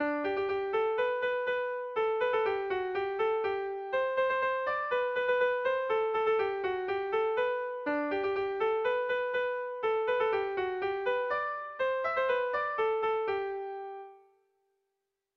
Kontakizunezkoa
Orbaitzeta < Pirinioak < Nafarroa < Euskal Herria
ABDABE